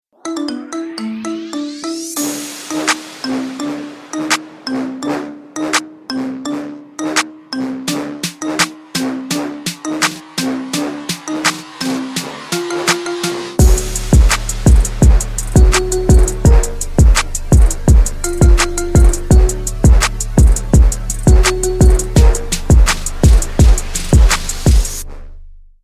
Рингтоны Без Слов